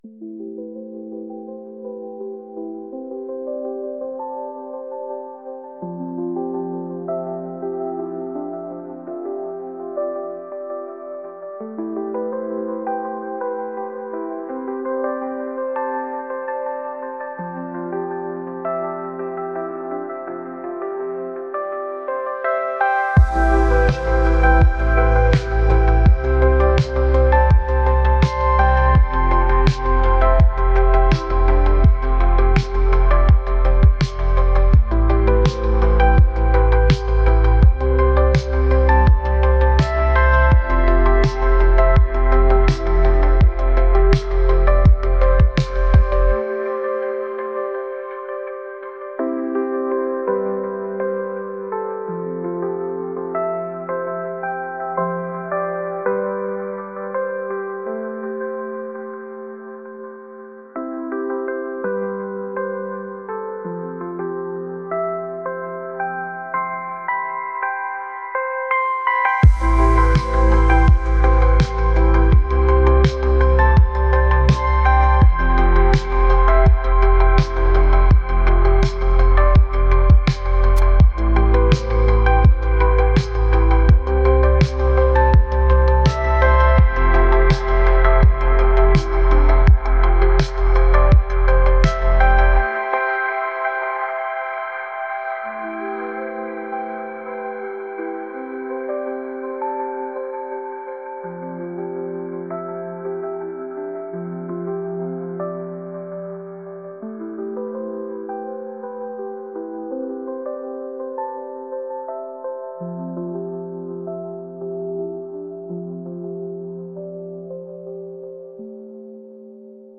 dreamy | atmospheric